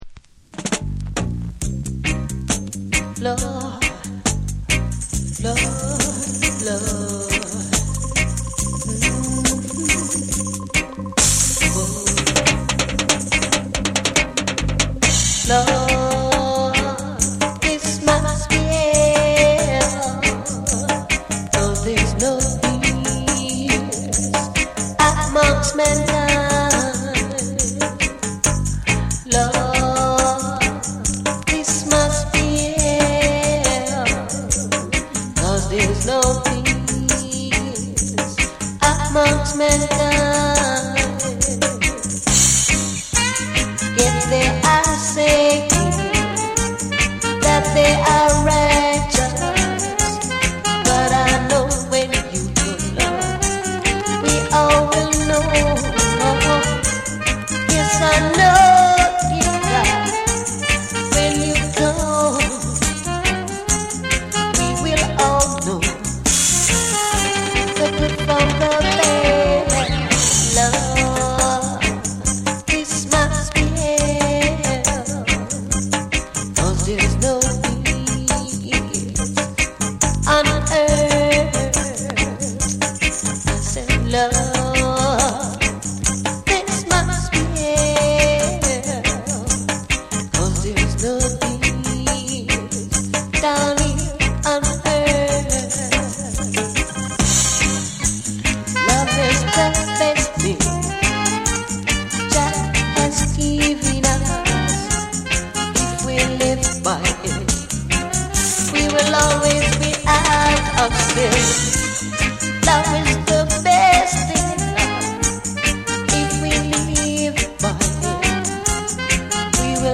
ルーツ色の濃い